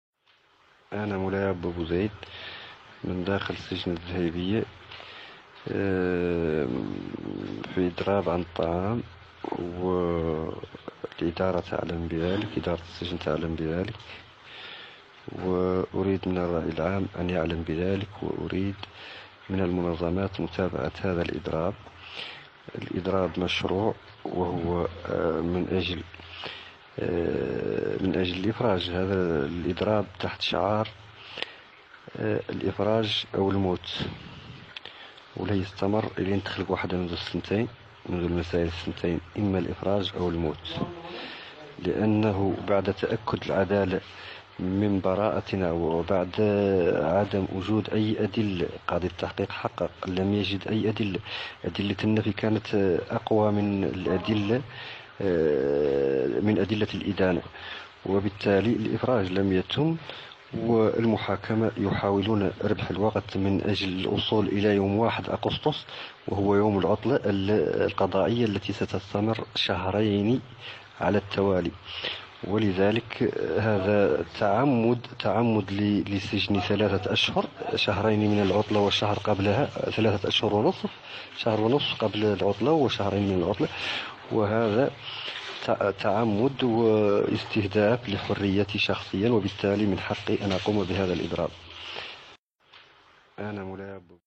تسجيل صوتي لمعتقل يحكي فظاعات سجون البوليساريو